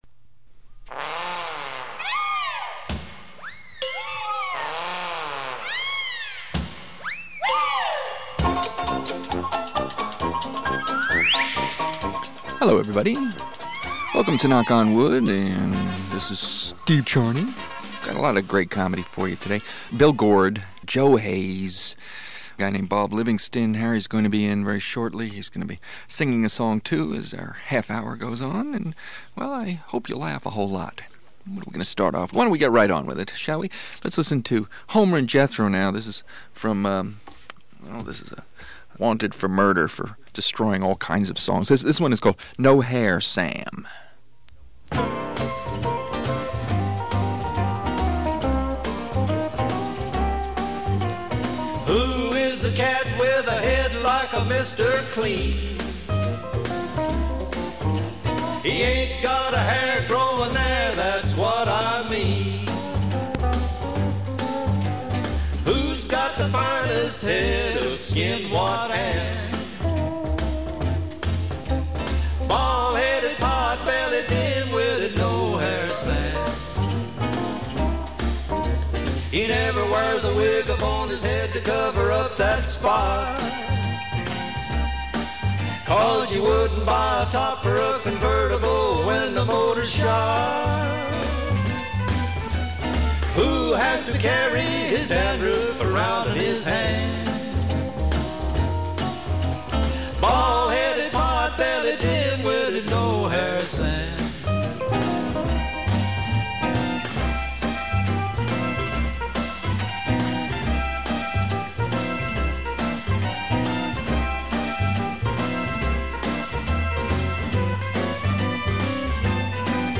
Comedy Show
Trout Fishing in America is a guest on this show.